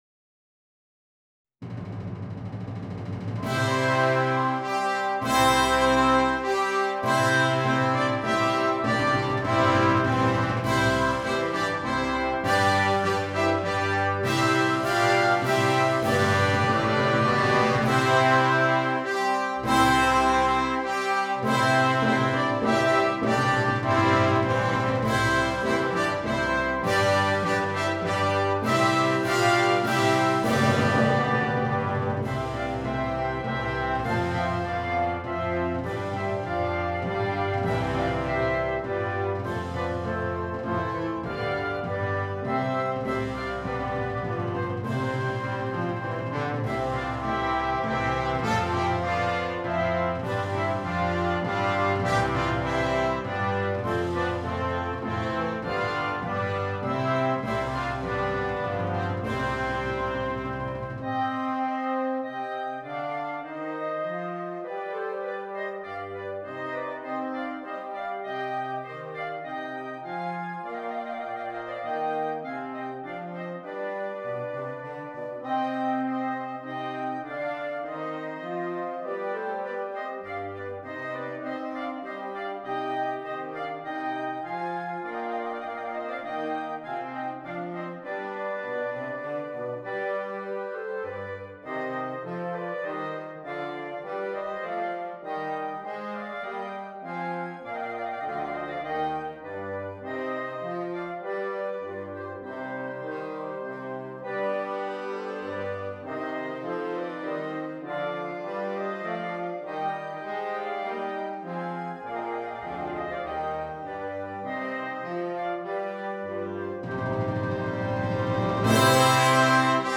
for Wind Band